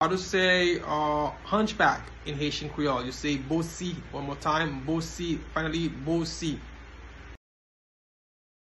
Pronunciation:
Listen to and watch “Bosi” pronunciation in Haitian Creole by a native Haitian  in the video below:
Hunchback-in-Haitian-Creole-Bosi-pronunciation-by-a-Haitian-Creole-teacher.mp3